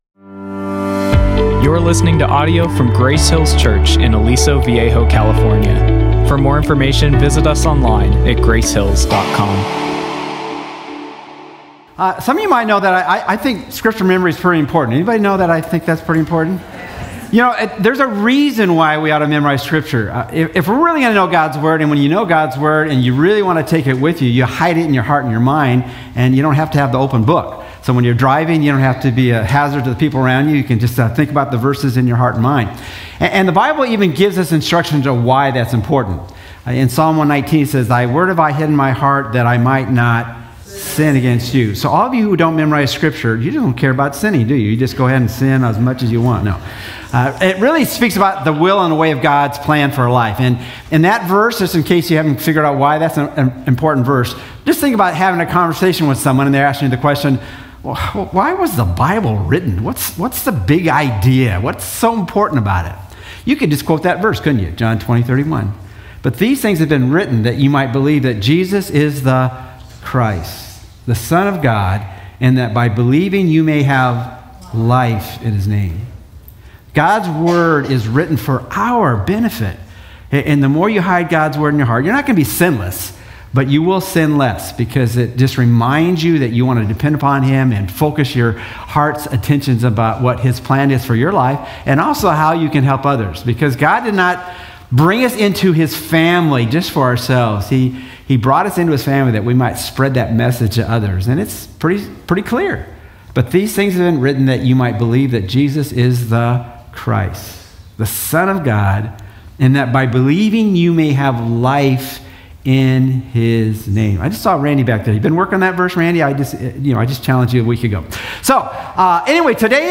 Listen online to this week's message or search our archive of messages by series, speaker or topic.